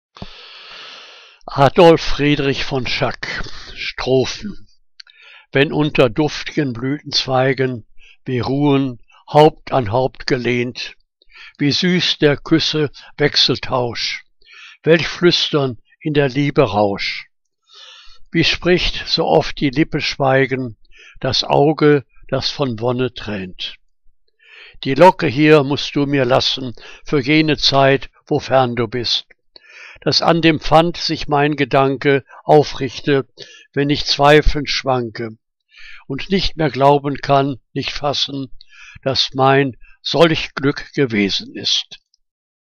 Liebeslyrik deutscher Dichter und Dichterinnen - gesprochen (Adolf Friedrich von Schack)